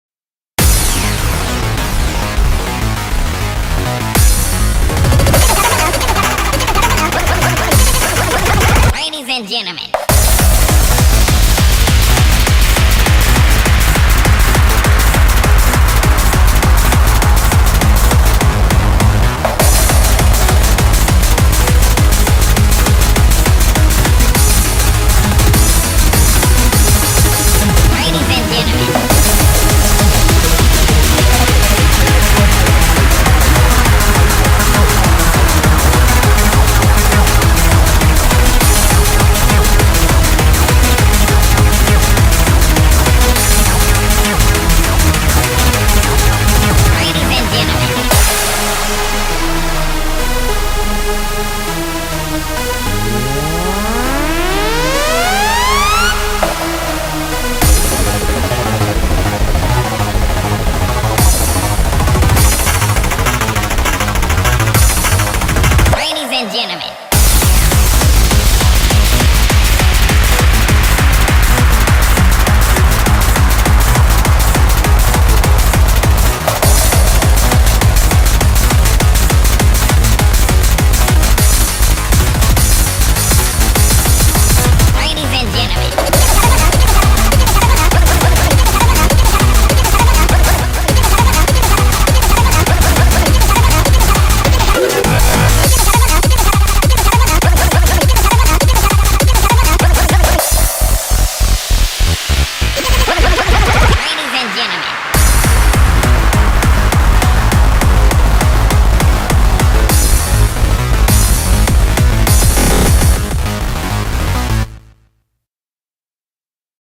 BPM202
Audio QualityPerfect (High Quality)